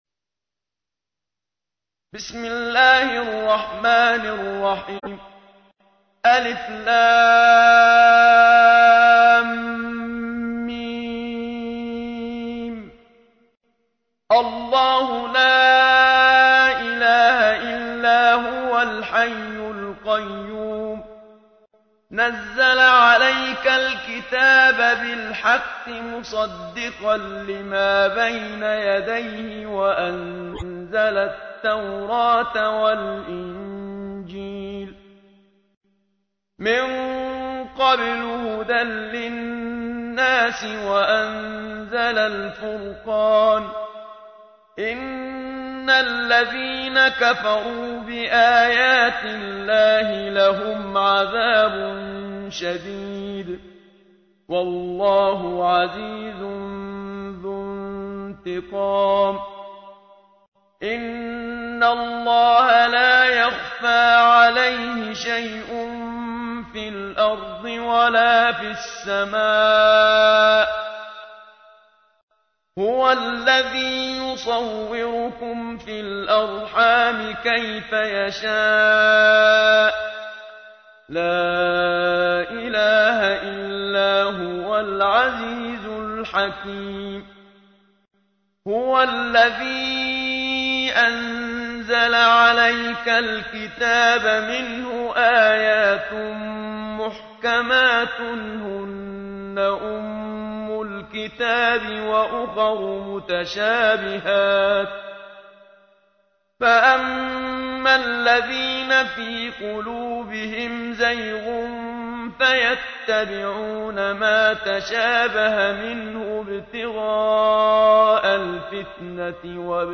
تحميل : 3. سورة آل عمران / القارئ محمد صديق المنشاوي / القرآن الكريم / موقع يا حسين